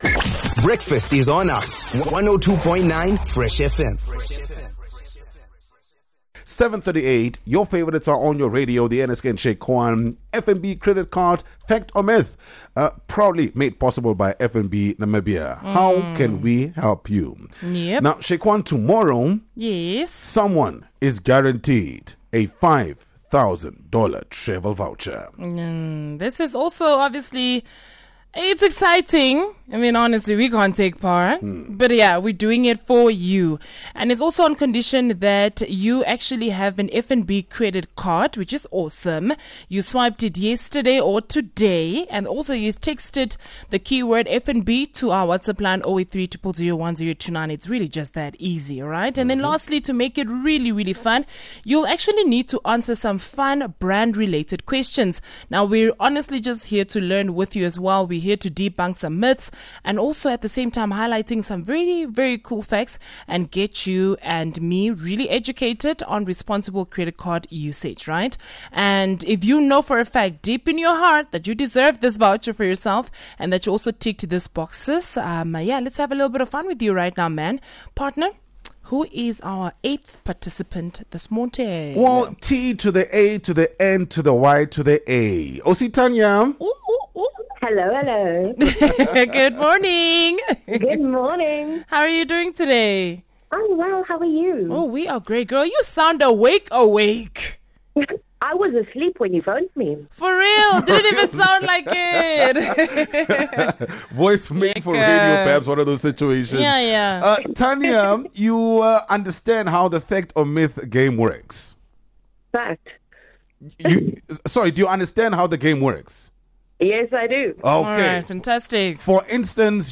Fresh FM and FNB are here to put you to the test — with a fun, fast-paced segment that’s all about busting myths and rewarding facts! A game in which you could walk away with a N$5,000 travel voucher!